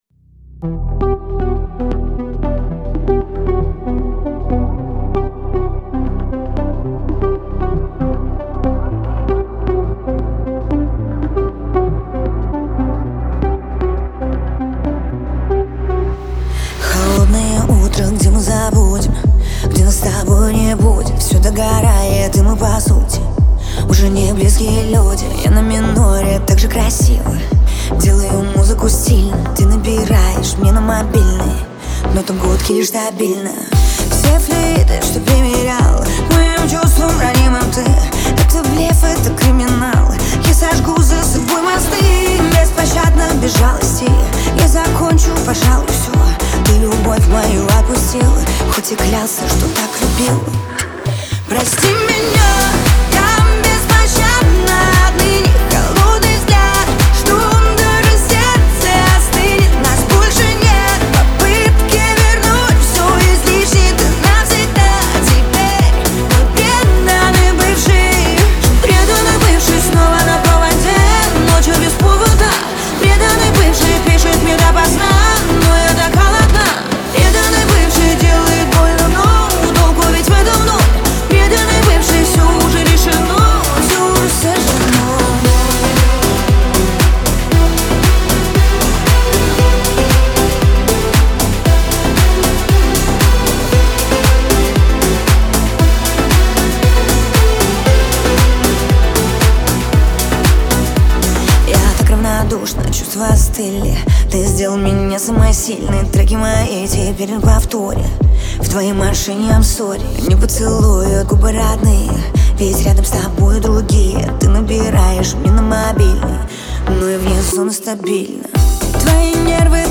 pop , эстрада
грусть